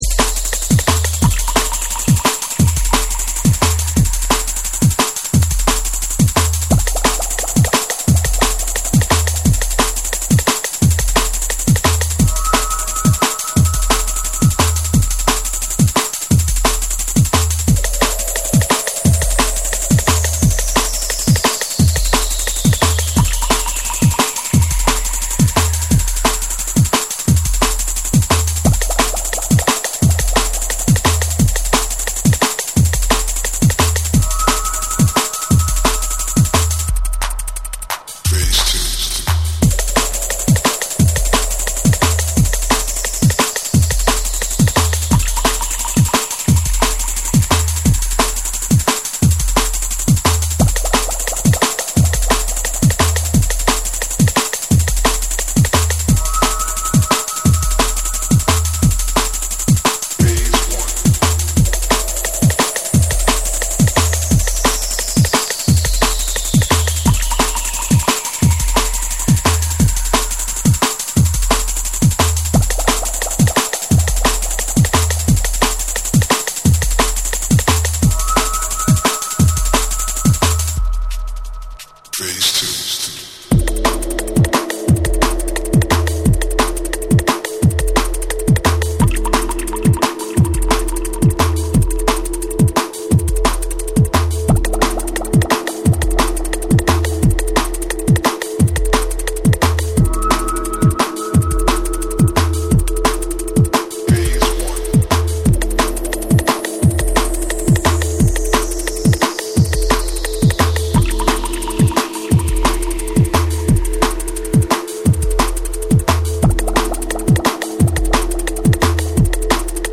ダークで重厚なベースラインと走感のあるリズムが相まる、アトモスフェリックなサウンドがフロアを強烈に揺さぶる
JUNGLE & DRUM'N BASS